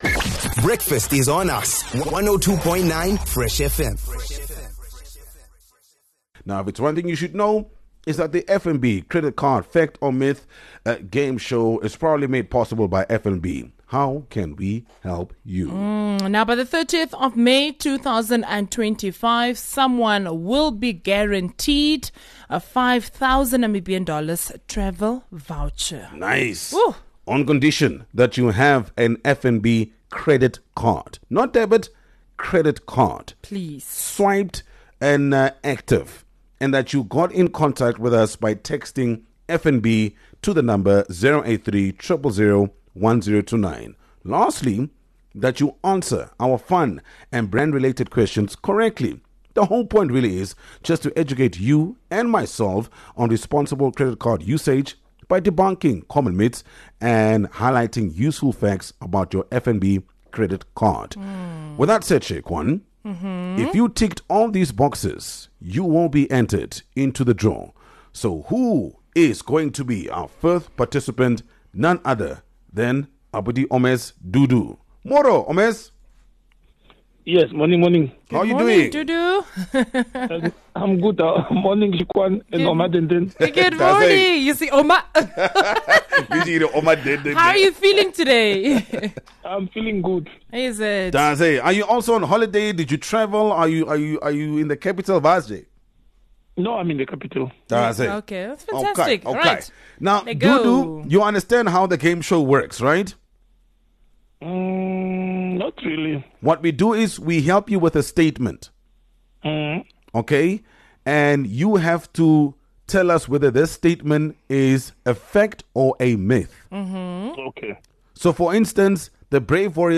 Think you’ve got your credit card knowledge on lock? Fresh FM and FNB are here to put you to the test — with a fun, fast-paced segment that’s all about busting myths and rewarding facts! A game in which you could walk away with a N$5,000 travel voucher!